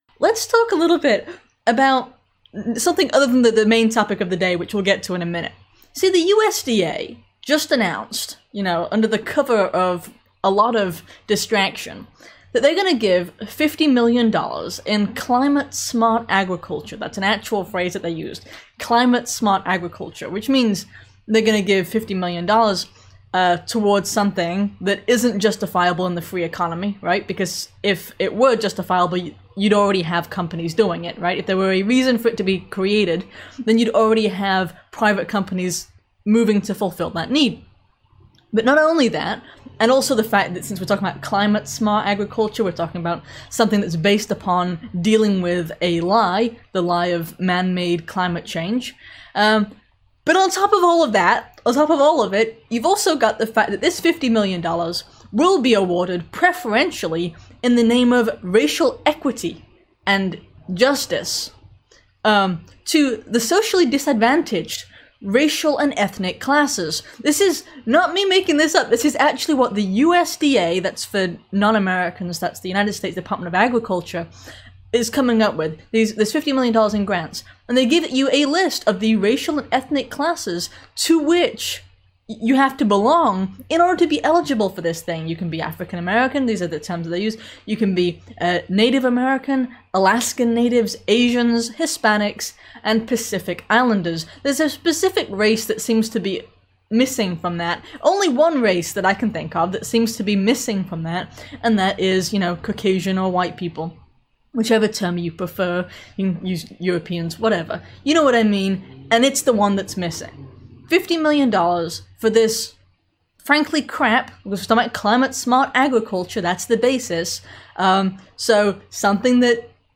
This live show covered how major corporations are covering up an official's use of the term "New World Order", the health service that published a "Dear White People" letter, Biden's attempt to vaccinate every working adult, and more.